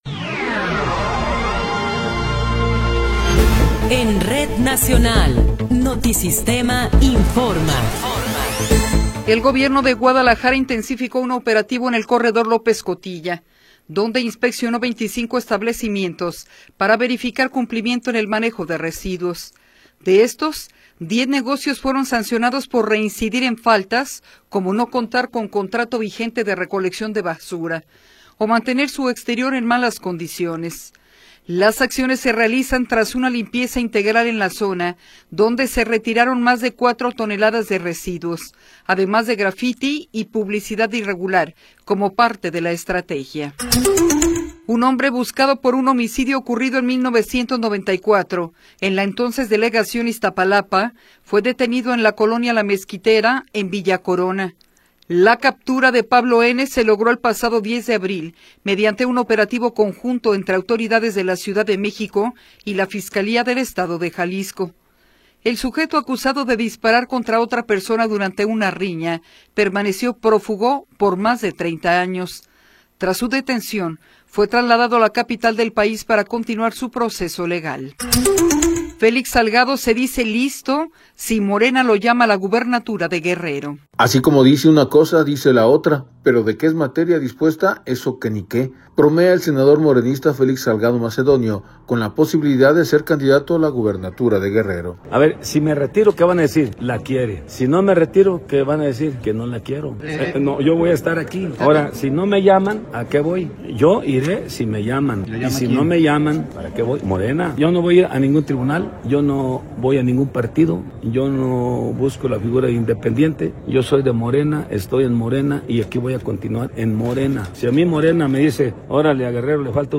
Noticiero 17 hrs. – 18 de Abril de 2026
Resumen informativo Notisistema, la mejor y más completa información cada hora en la hora.